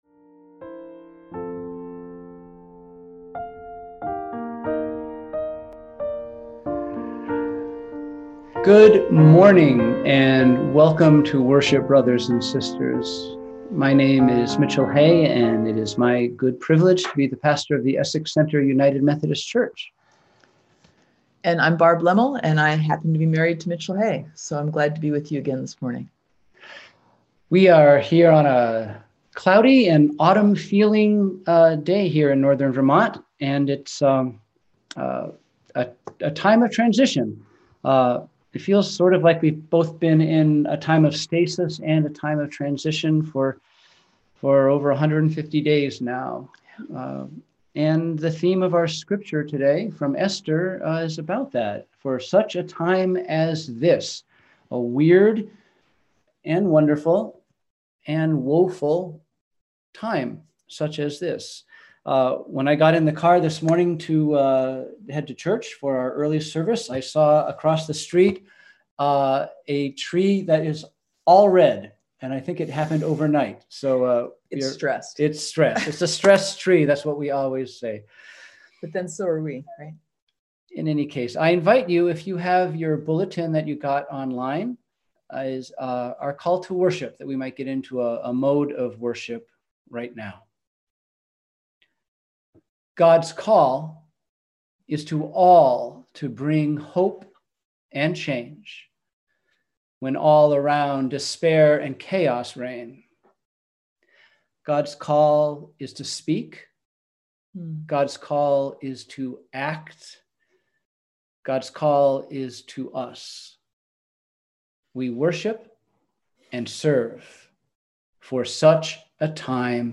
We held virtual worship on Sunday, August 30, 2020!